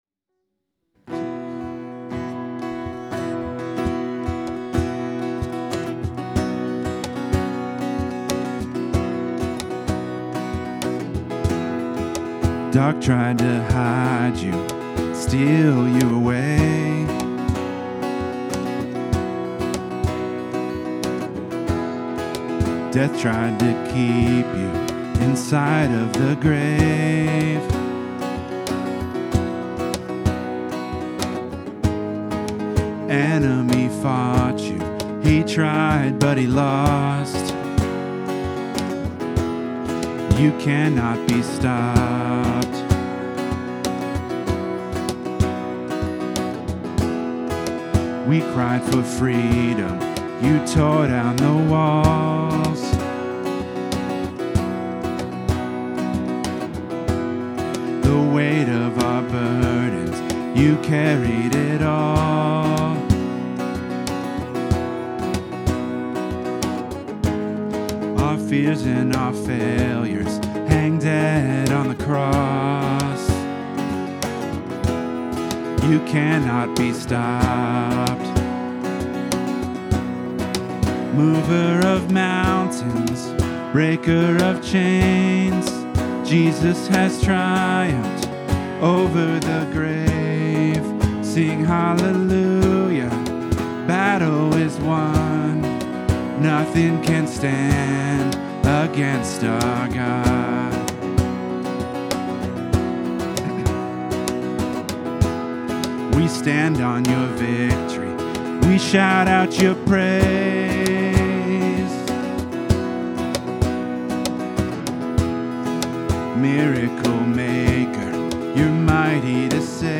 Worship 2026-02-22